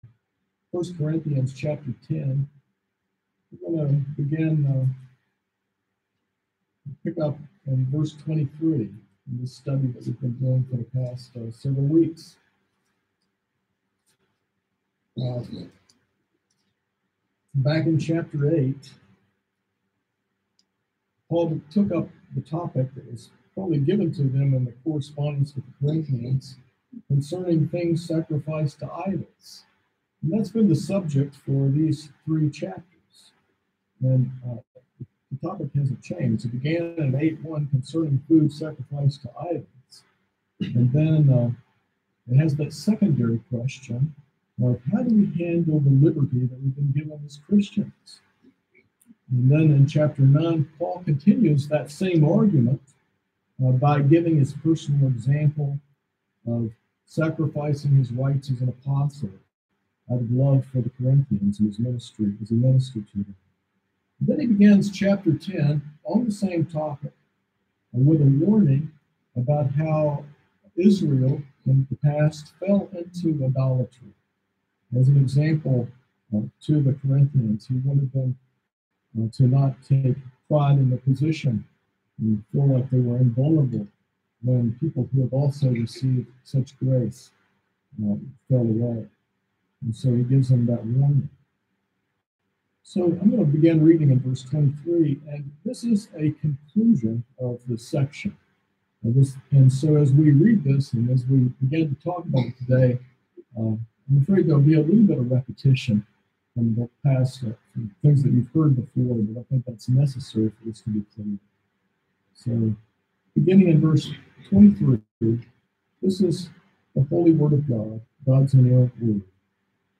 This sermon explores how believers should use their Christian liberty, emphasizing that all actions—eating, drinking, or anything else—must be done for the glory of God. It calls for choices that are spiritually profitable and edifying to others, especially in avoiding any appearance of idolatry or self-serving behavior.